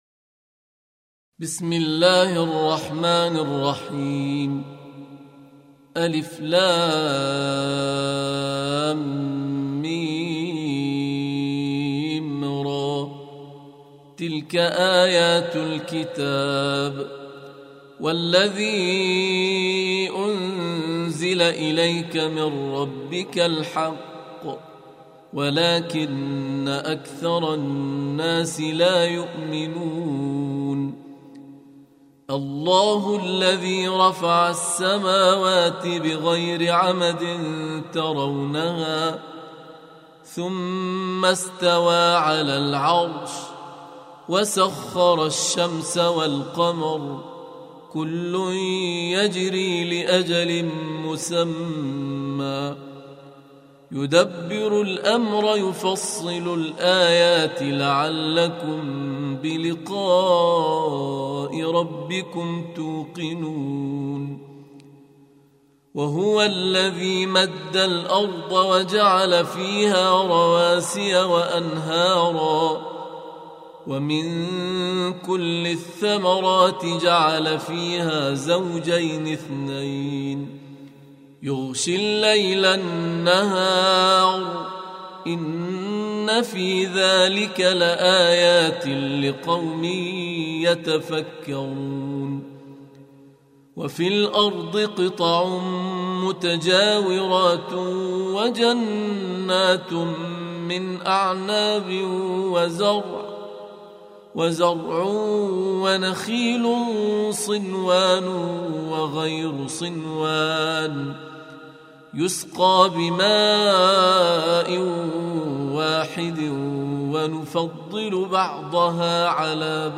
Surah Ar-Ra'd سورة الرعد Audio Quran Tarteel Recitation
حفص عن عاصم Hafs for Assem